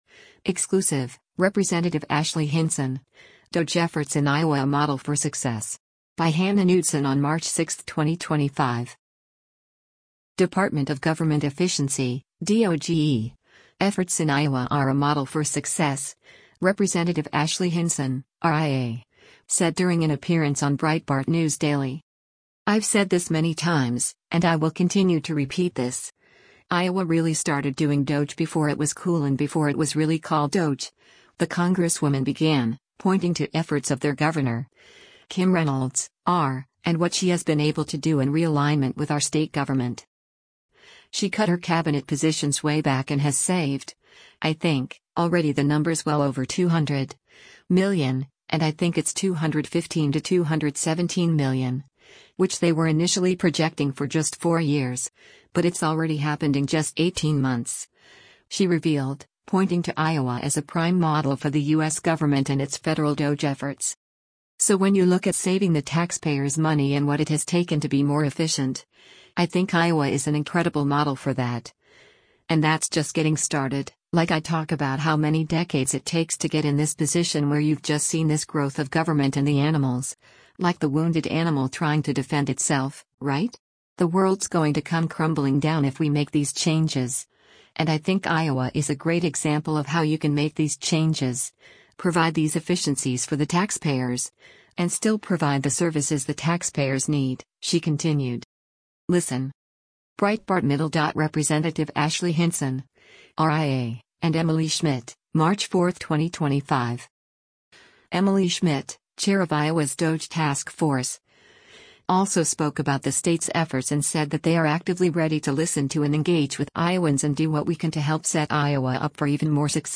Ashley Hinson speaks
Department of Government Efficiency (DOGE) efforts in Iowa are a “model for success,” Rep. Ashley Hinson (R-IA) said during an appearance on Breitbart News Daily.